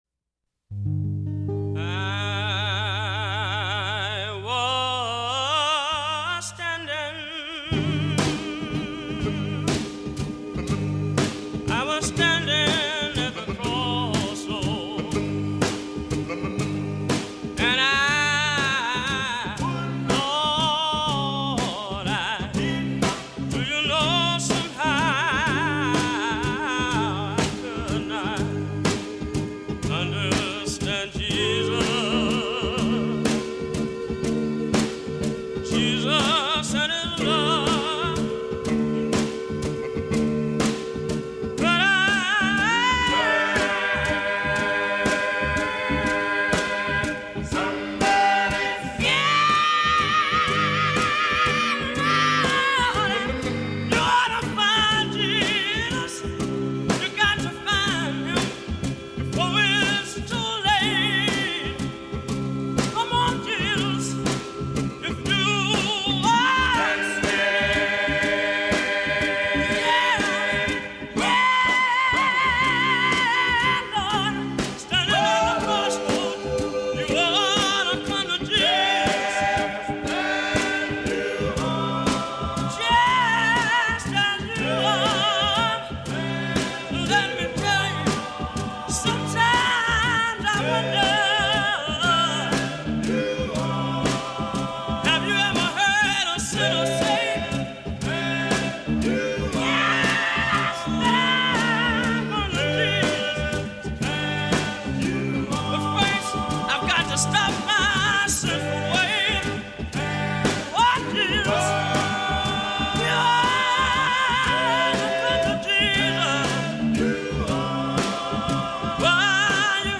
Scratchy vanity 45s, pilfered field recordings, muddy off-the-radio sounds, homemade congregational tapes and vintage commercial gospel throw-downs; a little preachin', a little salvation, a little audio tomfoolery.
Gospel
Field Recordings